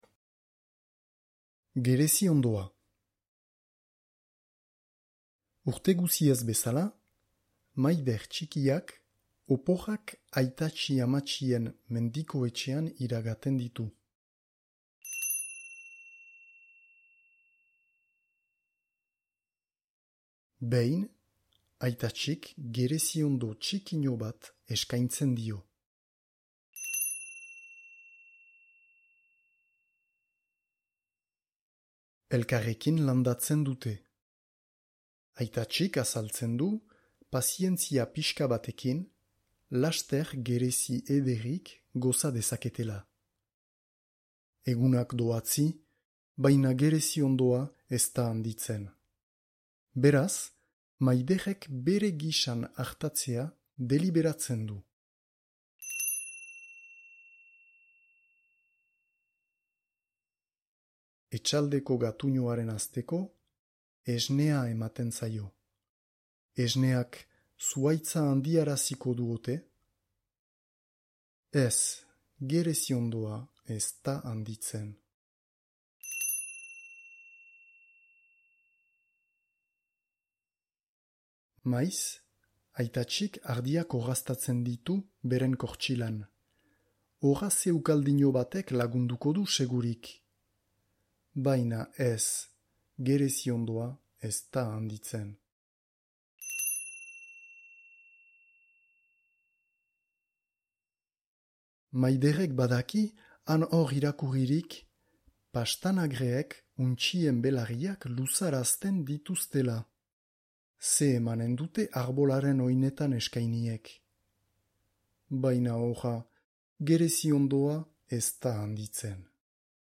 Gereziondoa - batuaz - ipuina entzungai